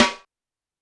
Drums_K4(58).wav